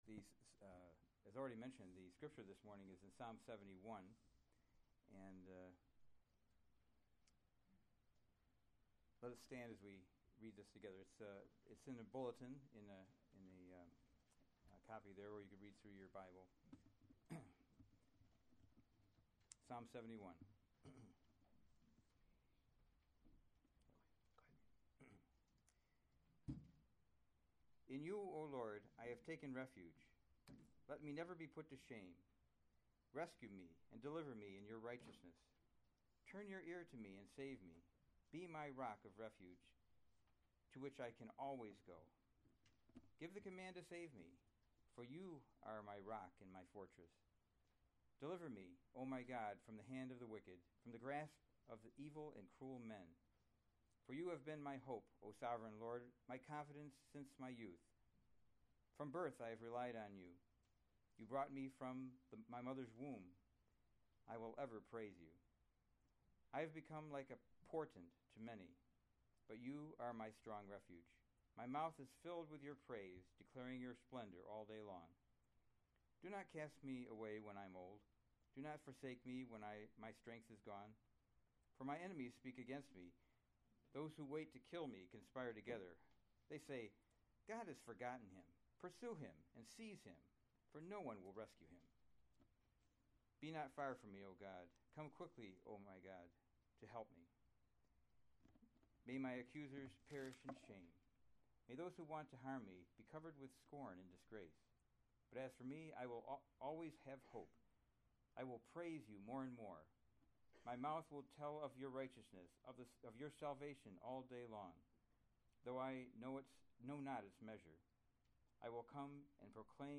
July 11, 2015 Psalms – Summer Series series Weekly Sunday Service Save/Download this sermon Psalm 71 Other sermons from Psalm Forsake Me Not When My Strength Is Spent 71:1 In you, […]